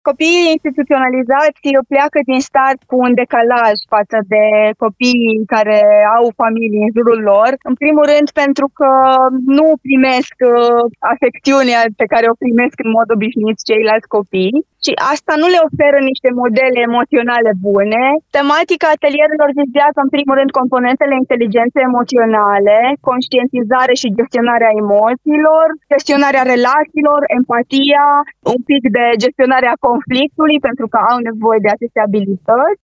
Prin joc, povești și experiențe interactive, cei mici vor exersa abilități care să îi ajute să se descurce mai bine în viața de zi cu zi și să se adapteze în relațiile cu ceilalți, a spus la Radio Timișoara